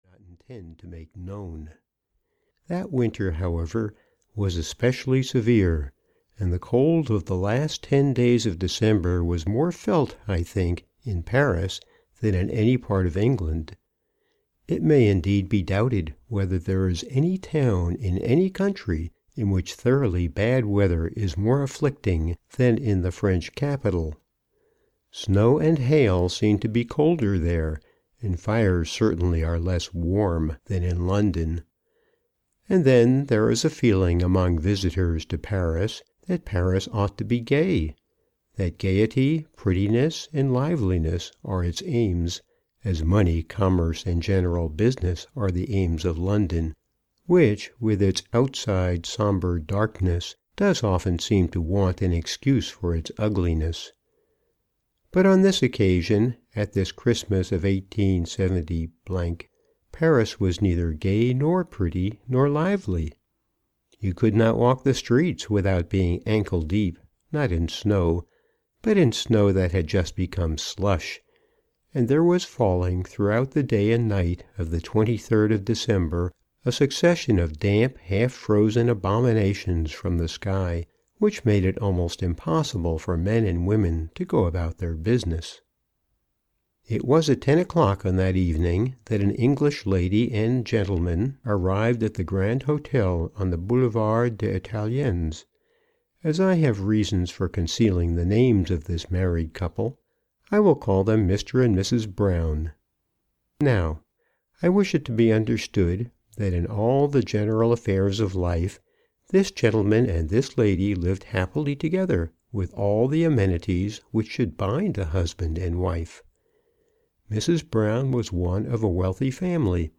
Christmas at Thompson Hall: A Mid-Victorian Christmas Tale (EN) audiokniha
Ukázka z knihy